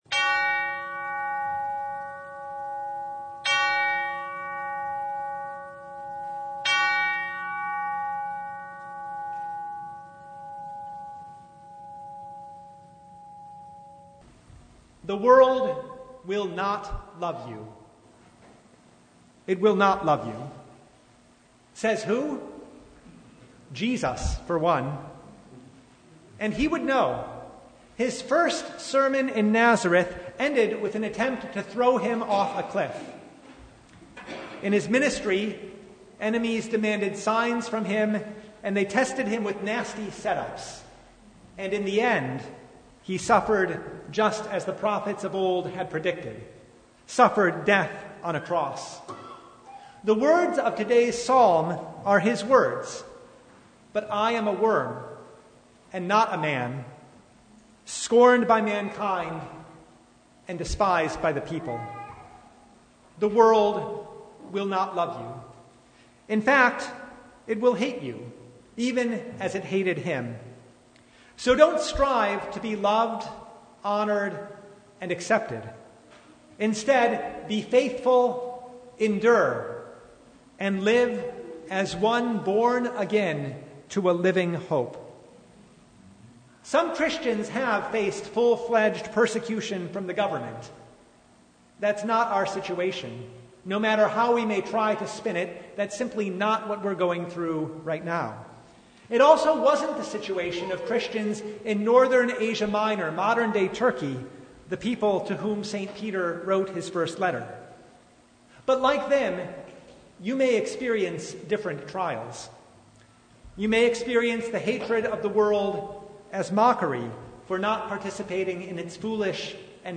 Service Type: Lent Midweek Noon